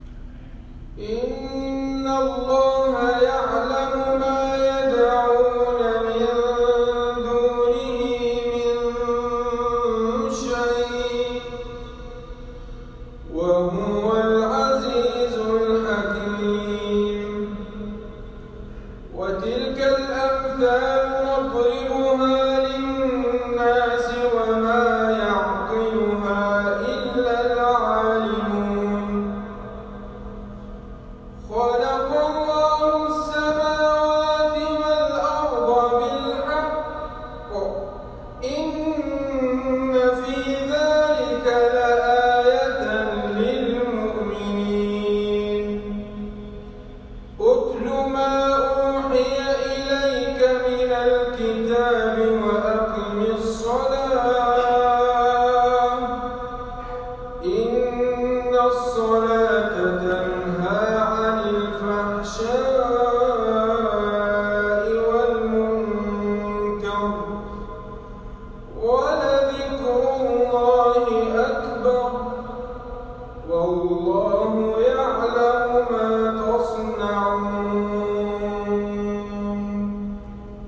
تلاوة هادئة من تسجيلي لأحد الإخوة
تلاوة هادئة من تسجيلي من صلاة العشاء من جامع الإمام أحمد بن حنبل في الجبيل الصناعية لأحد الإخوة التابعين للجمعية الخيرية لتحفيظ القرآن الكريم في 26/4/2019